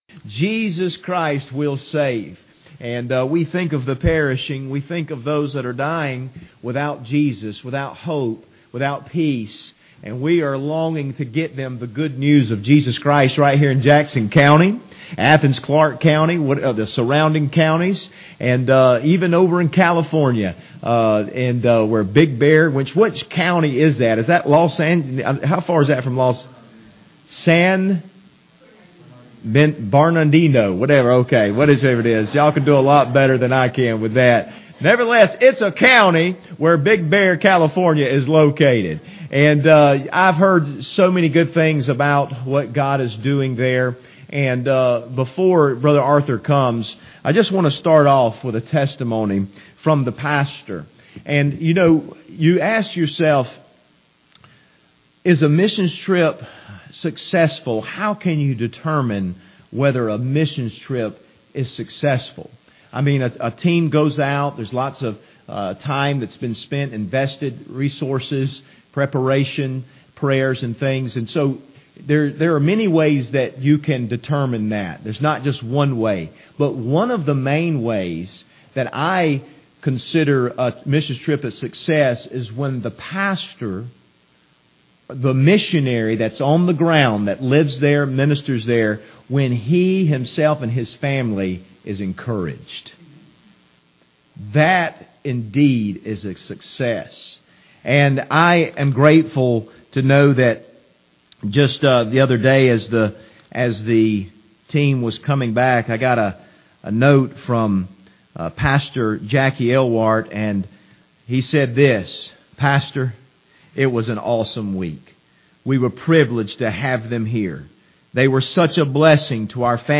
Big Bear California Mission Team Testimonies
Service Type: Wednesday Evening